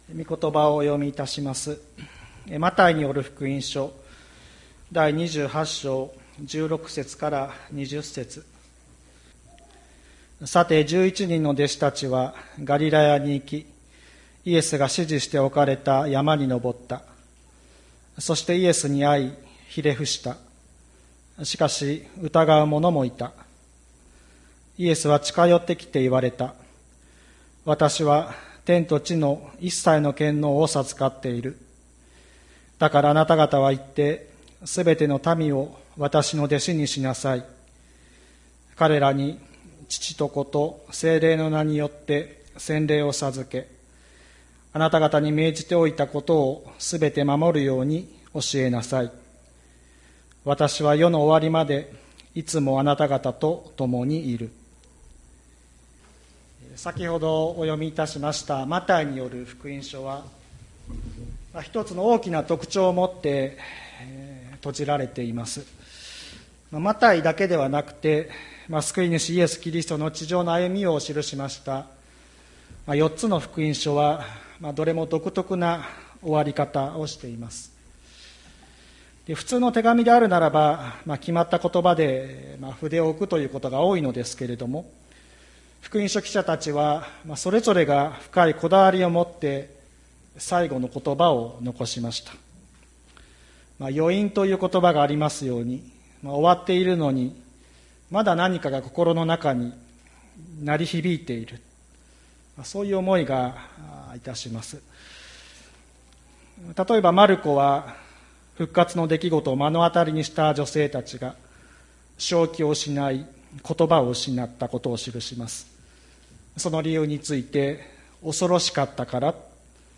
2020年09月06日朝の礼拝「復活の主に遣わされて」吹田市千里山のキリスト教会